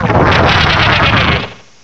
cry_not_gumshoos.aif